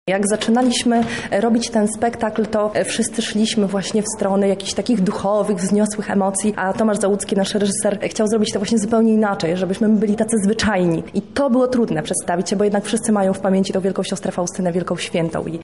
aktorka Teatru.